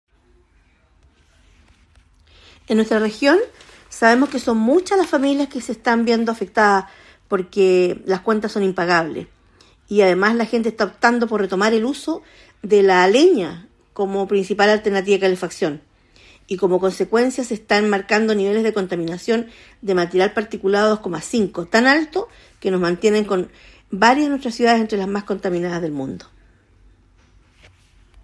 Diputada Marcia Raphael